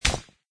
plasticstone.mp3